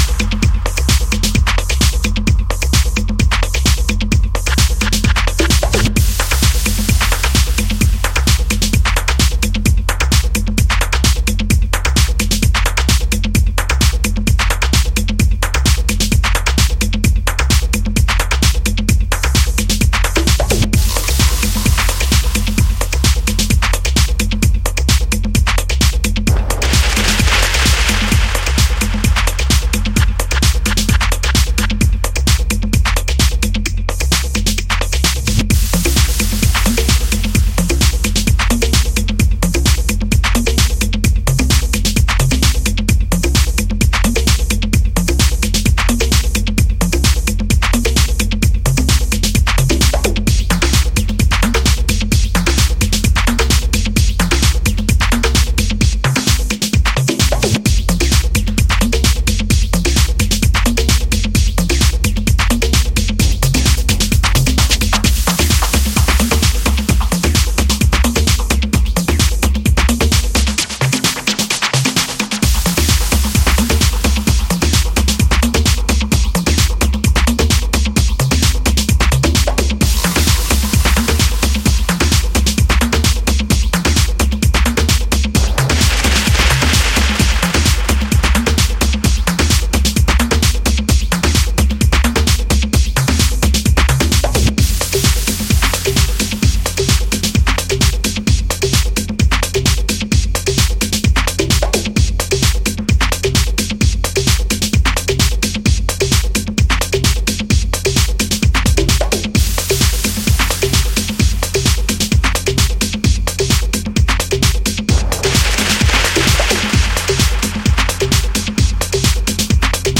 tech-house